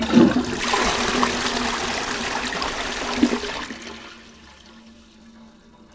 flush.wav